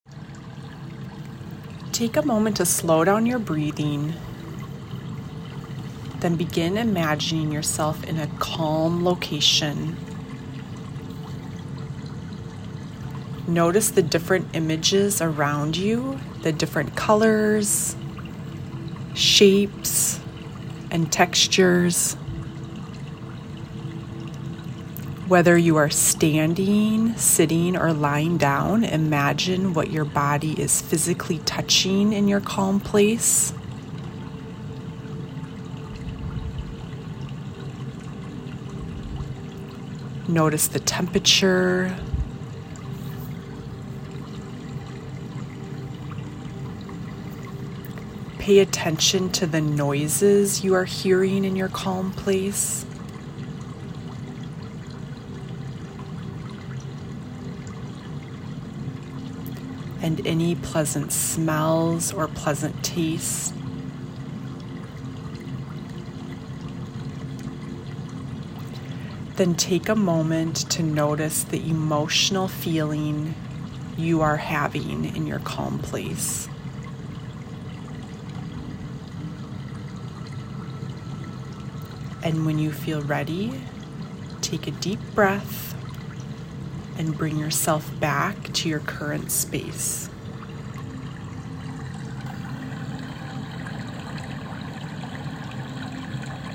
(1.5 minute meditation)